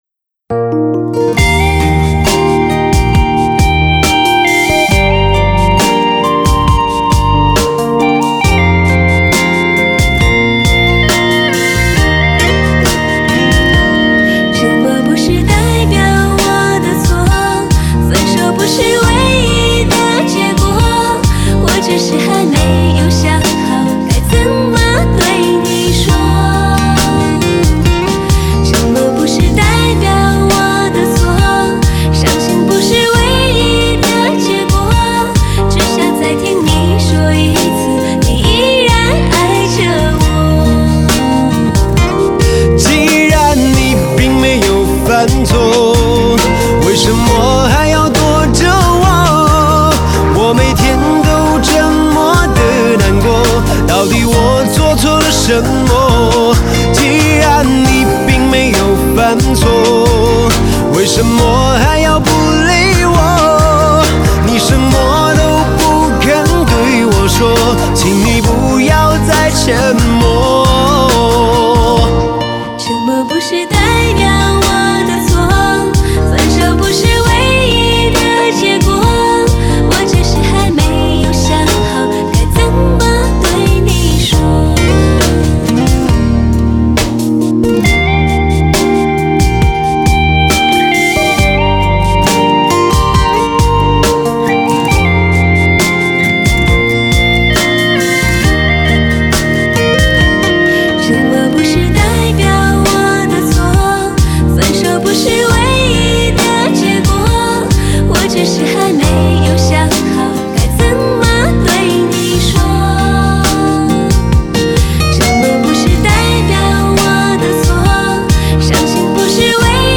类别: 摇滚